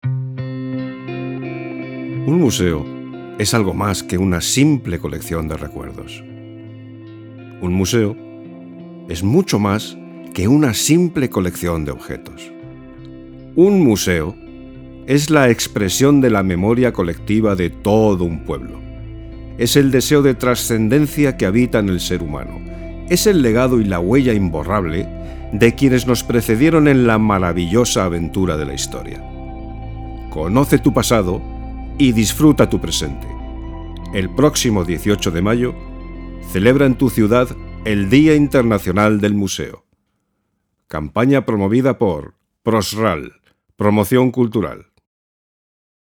A mature and authoritative, reliable and cordial voice.
Locución institucional (Día Internacional del Museo, texto propio).
Castelanian
Middle Aged